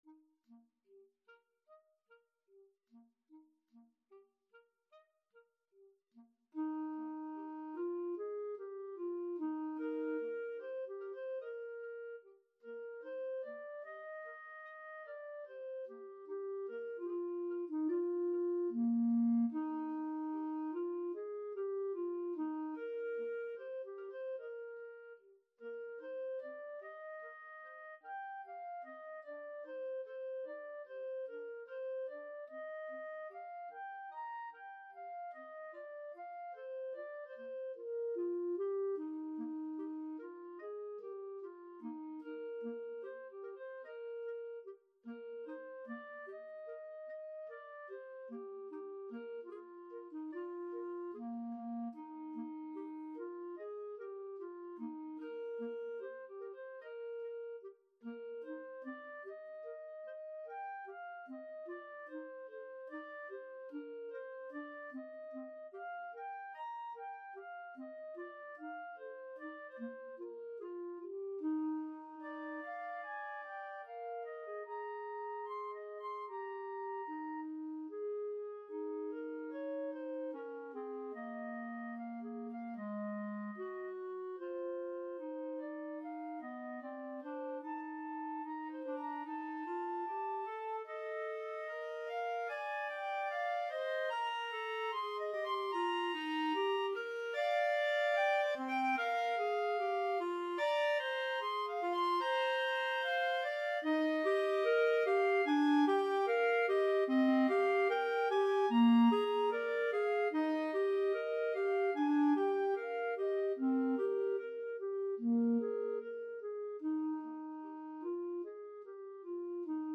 Free Sheet music for Clarinet Duet
Eb major (Sounding Pitch) F major (Clarinet in Bb) (View more Eb major Music for Clarinet Duet )
Andantino quasi allegretto ( = 74) (View more music marked Andantino)
Classical (View more Classical Clarinet Duet Music)